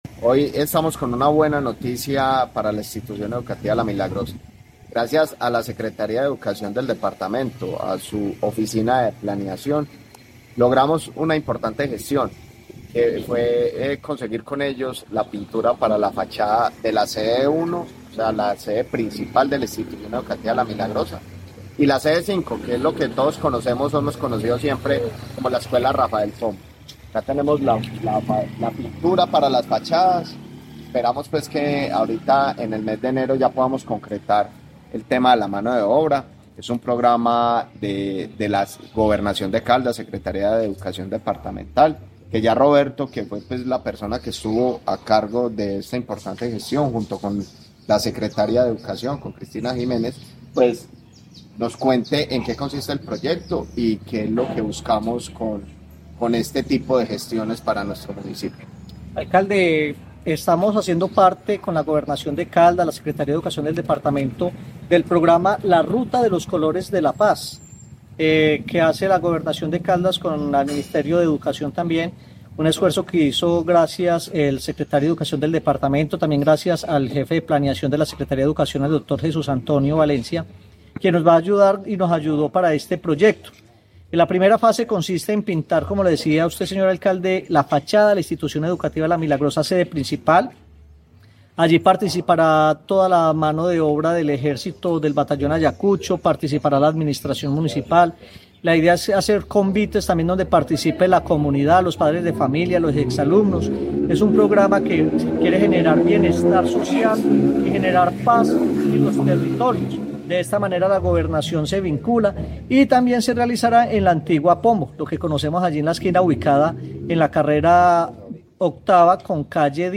Alcalde de Viterbo, Néstor Javier Ospina Grajales.
Alcalde-de-Viterbo-y-funcionario-Alcaldia-de-Viterbo-ruta-de-los-colores.mp3